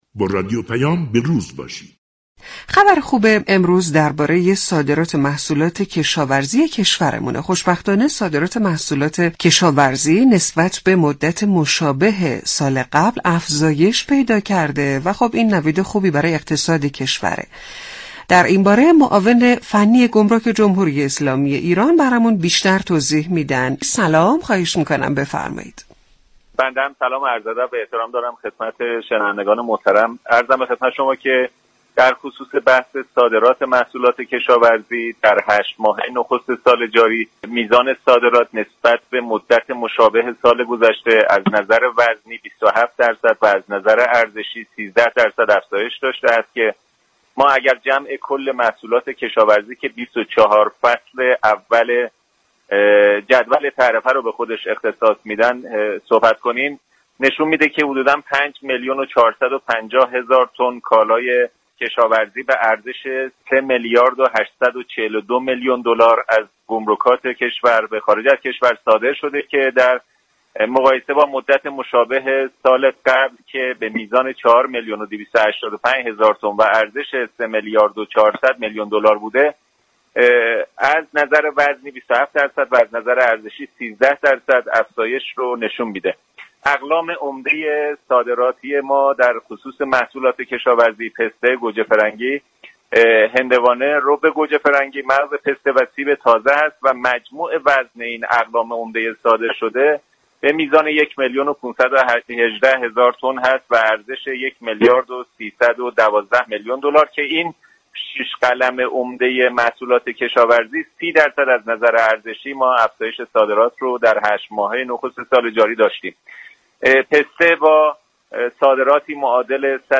اروَنقی ، معاون فنی و امور گمركی گمرك ایران ، در گفتگو با رادیو پیام ، از رشد 13 درصدی ارزش صادرات محصولات كشاورزی در هشت‌ ماه نخست سال جاری نسبت به مدت مشابه سال 98 خبر داد .